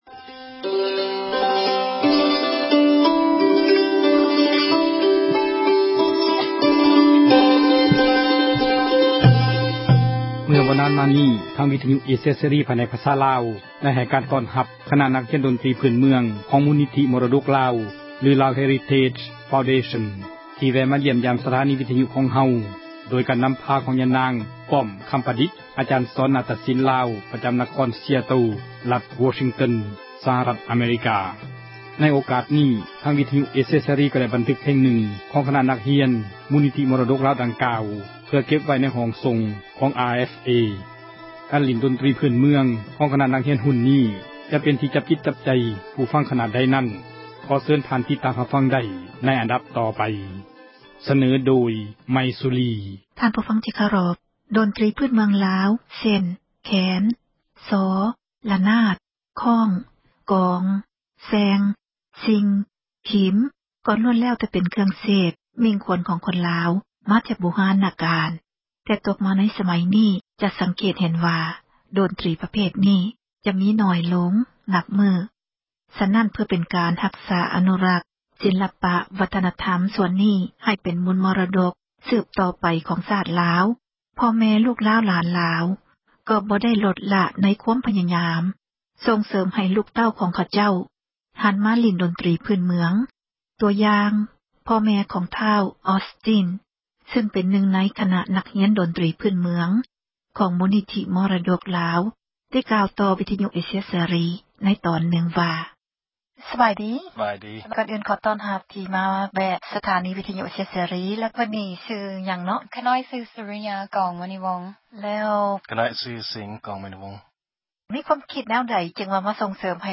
ໃນໂອກາດນີ້ ທາງວິທຍຸ ເອເຊັຍເສຣີ ກໍໄດ້ບັນທຶກ ສຽງເພງລາວເດີມ ເພງນຶ່ງ ຂອງຄນະນັກຮຽນ ມູລນິທິ ມໍຣະດົກລາວ ເພື່ອເກັບໄວ້ ໃນຫ້ອງສົ່ງ ຂອງ RFA ແລະ ເພື່ອເຜີຍແພ່ ການຫລີ້ນດົນຕຣິ ພື້ນເມືອງ ຂອງຄນະນັກຮຽນ ລູ້ນນີ້ ວ່າຈະເປັນທີ່ ຈັບຈິດຈັບໃຈ ຜູ້ຟັງຂນາດໃດ.